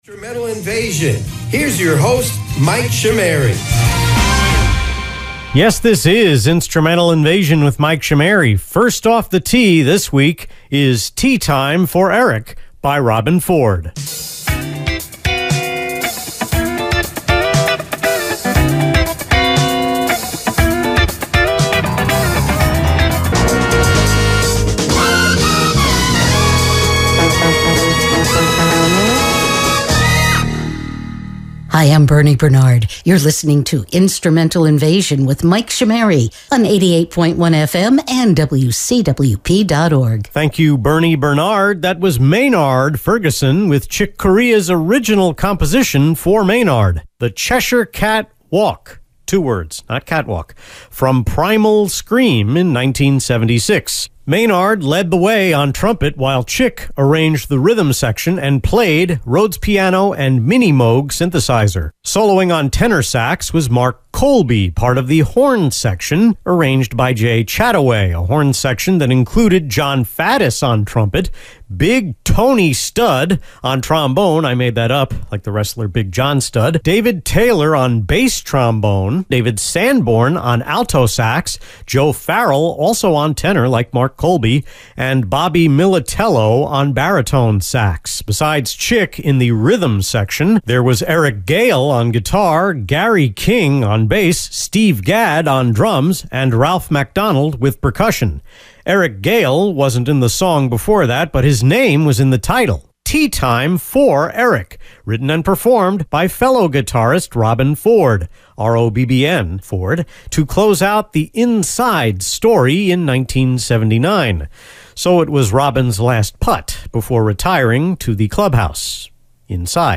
The airchecks were mostly recorded off the FM internet stream at my Wantagh home via desktop PCs in my bedroom and the guest room.